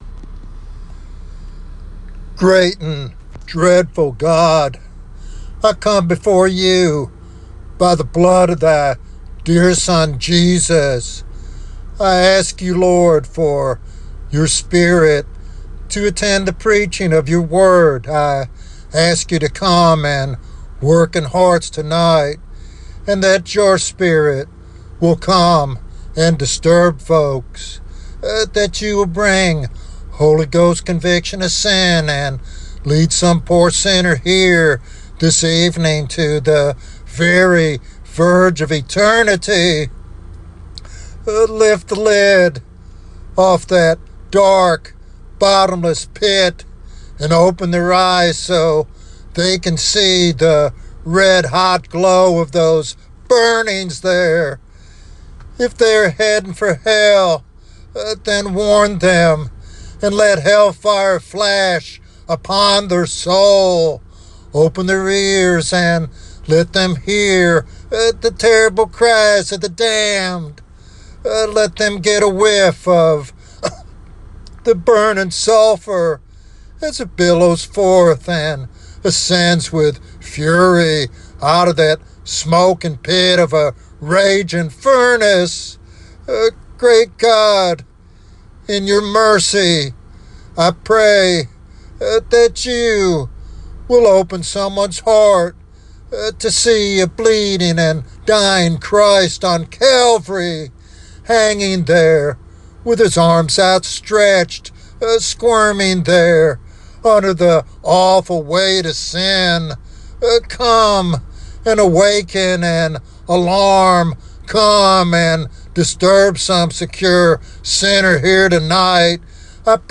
This sermon challenges believers and unbelievers alike to consider their eternal destiny seriously.